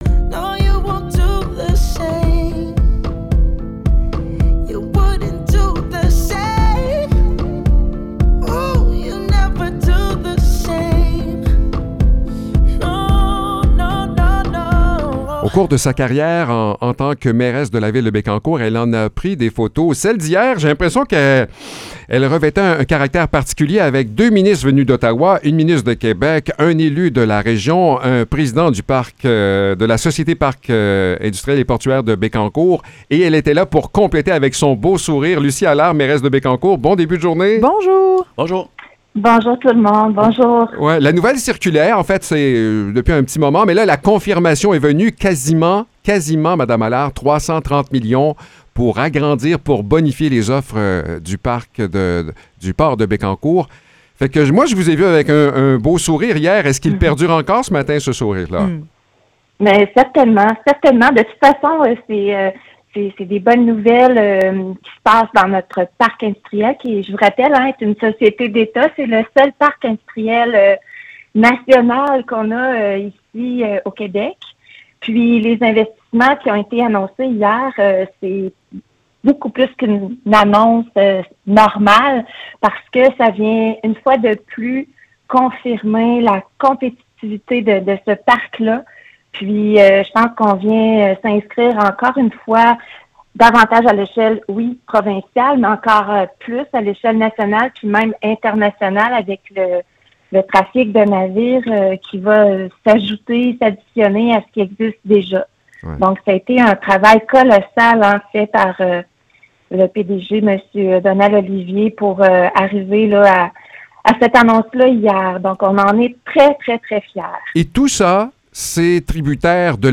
Échange avec la mairesse de Bécancour
Lucie Allard, mairesse de Bécancour, réagit à l’annonce du gros investissement pour le port de Bécancour.